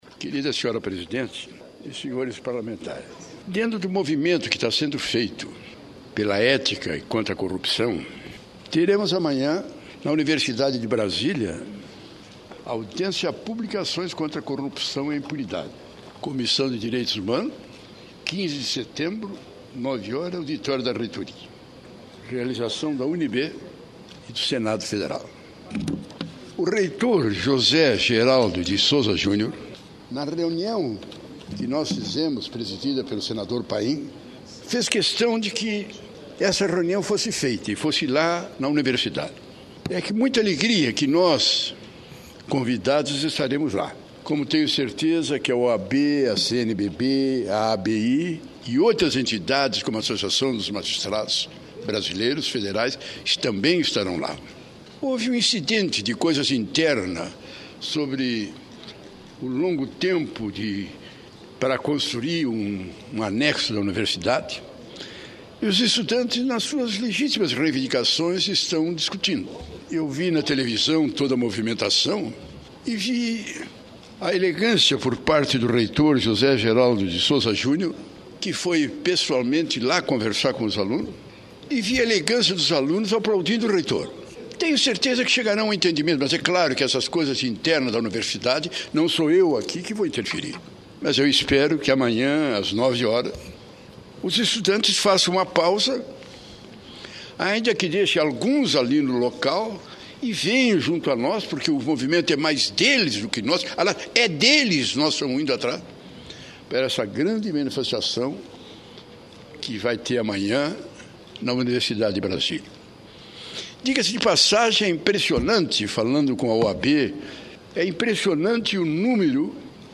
O senador Pedro Simon (PMDB-RS) anunciou que amanhã (15), às 9h, no auditório da reitoria da UnB, haverá uma audiência pública contra a corrupção e a impunidade. O senador disse esperar uma grande mobilização no Brasil neste sentido e que a participação dos jovens tem sido fundamental nesses momentos.